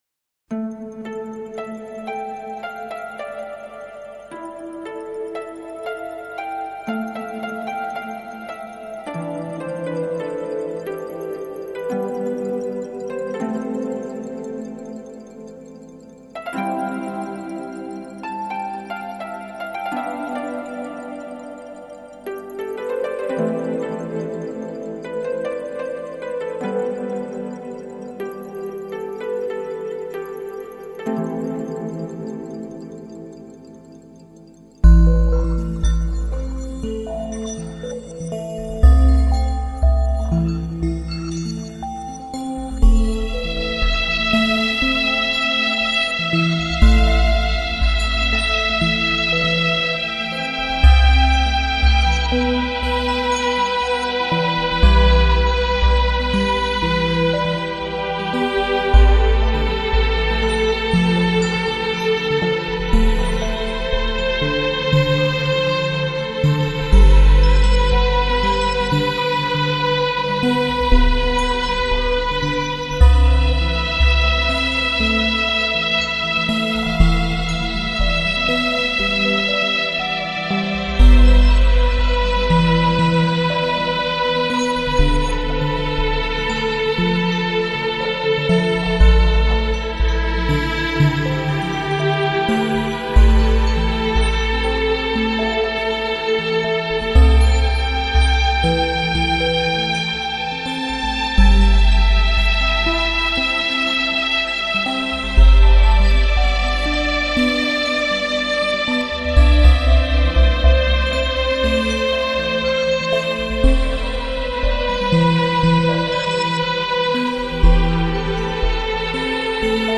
Жанр: Ambient, Berlin-School